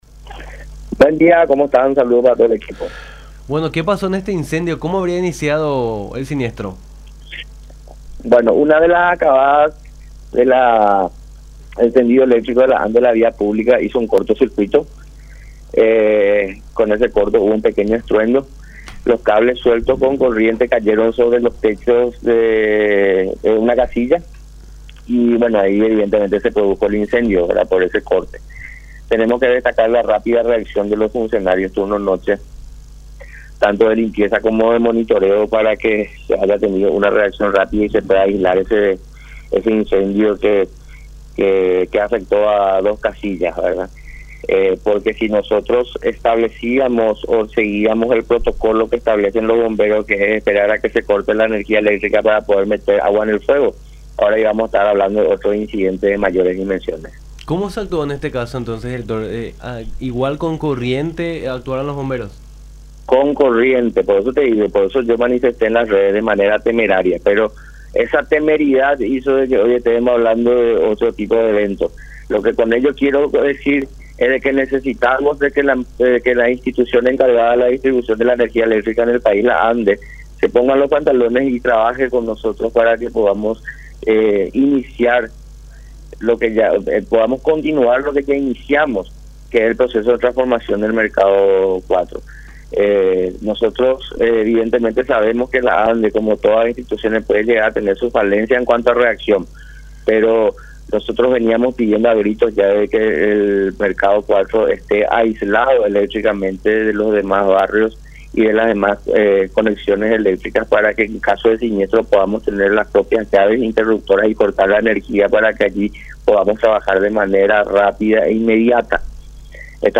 en diálogo con Nuestra Mañana por La Unión.